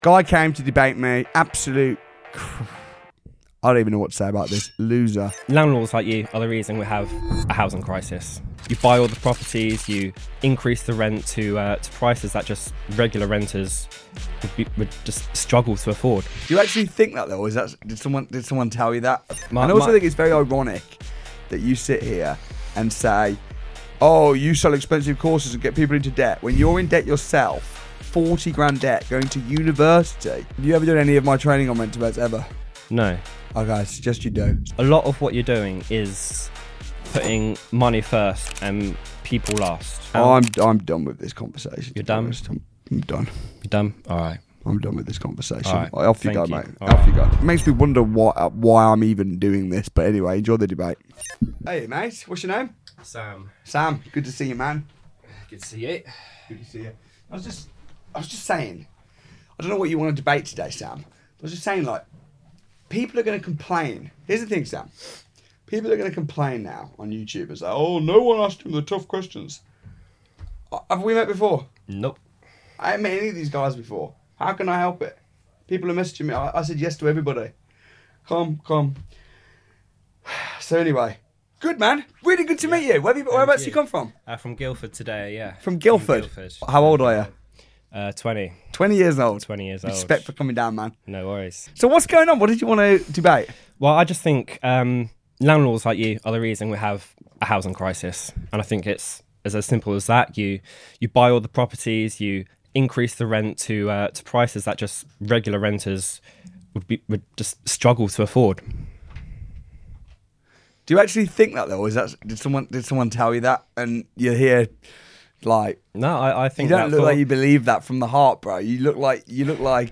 This isn’t your typical polite podcast — it’s a raw, unfiltered debate about wealth, property, and ethics in the UK.